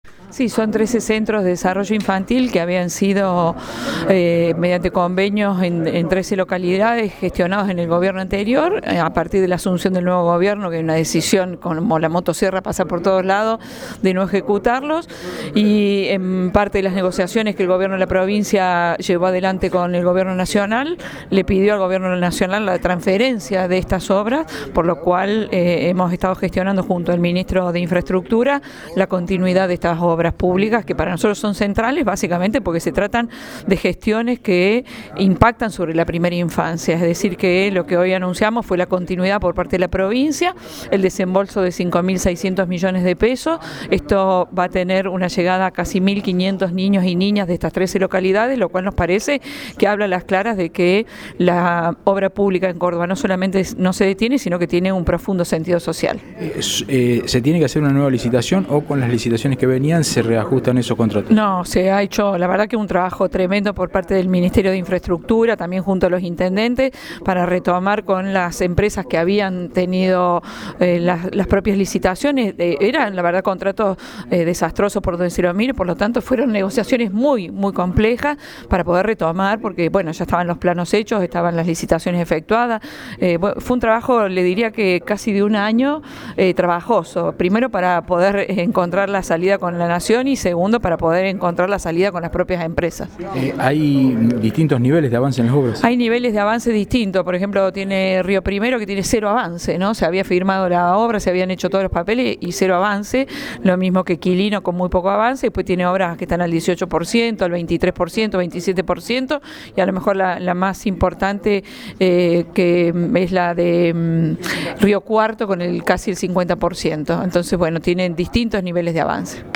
Audio: Liliana Montero (Ministra de Desarrollo Humano).